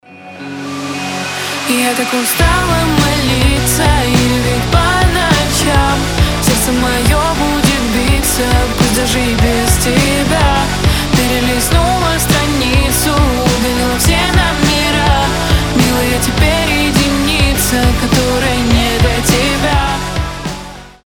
Грустные Мужской голос